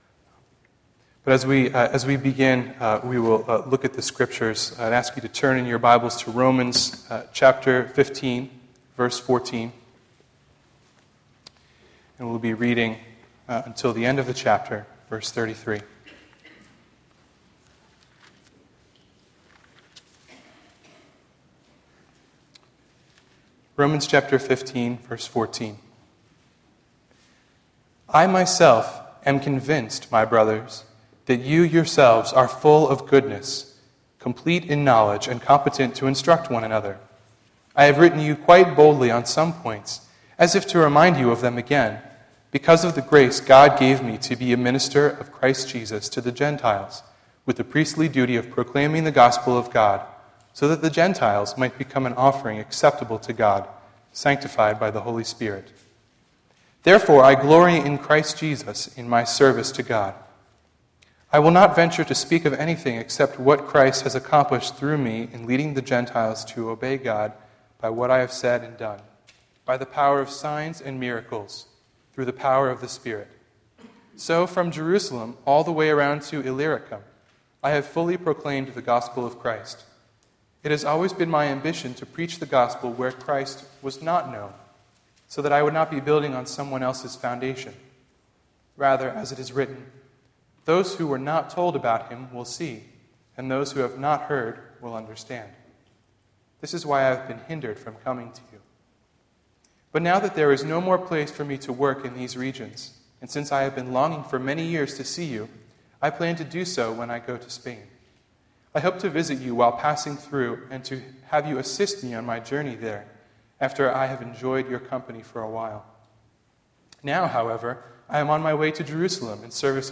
A sermon at Preakness Valley United Reformed Church in Wayne, NJ, on April 22, 2012.